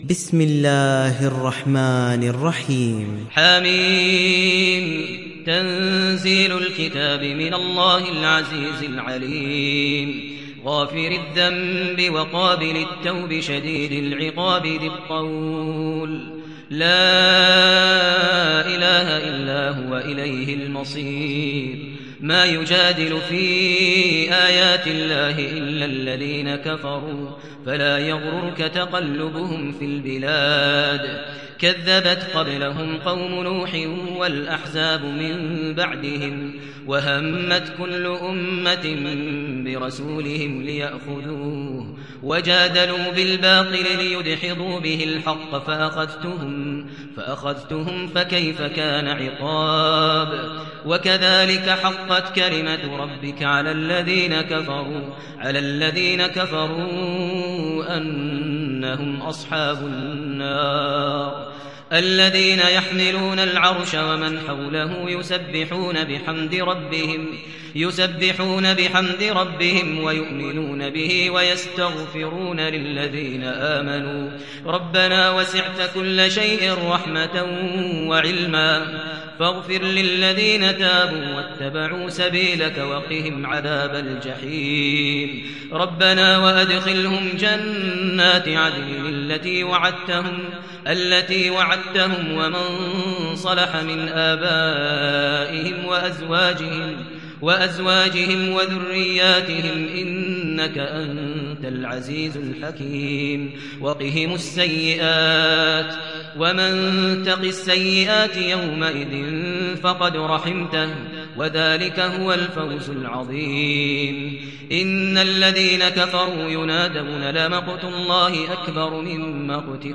دانلود سوره غافر mp3 ماهر المعيقلي روایت حفص از عاصم, قرآن را دانلود کنید و گوش کن mp3 ، لینک مستقیم کامل